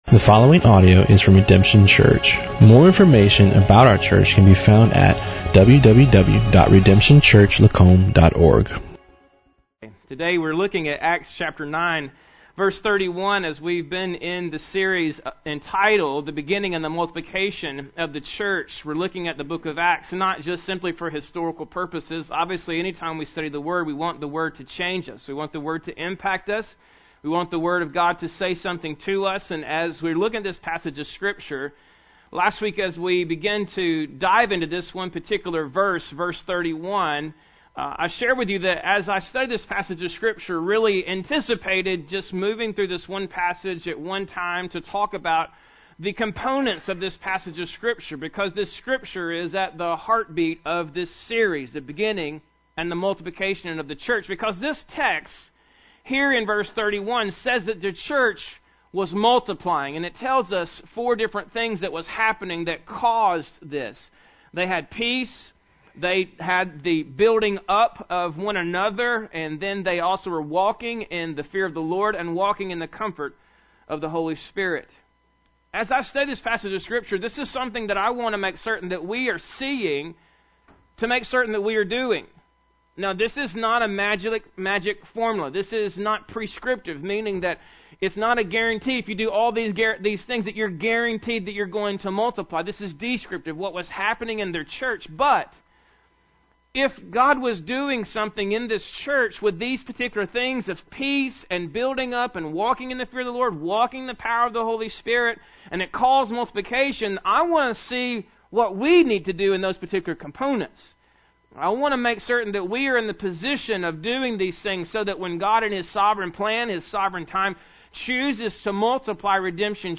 Bible Text: Acts 9:31 | Preacher